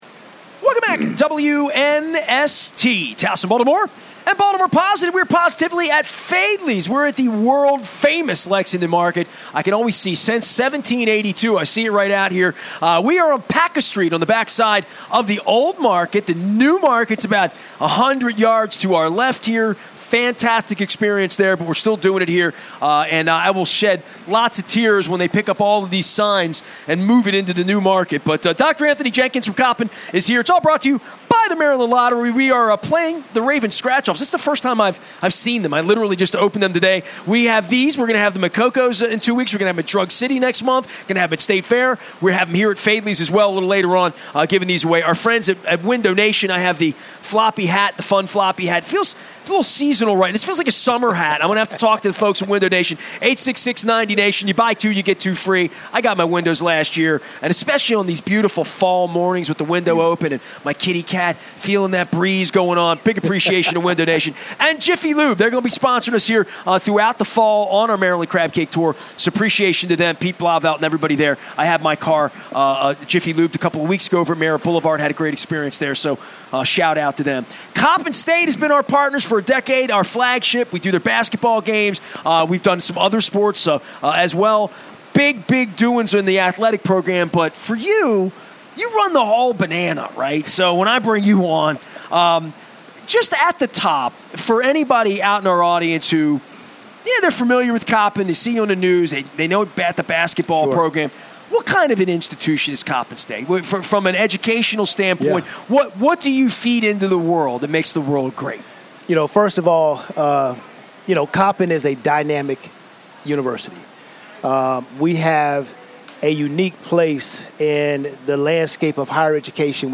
on the Maryland Crab Cake Tour at Faidley's Seafood in Lexington Market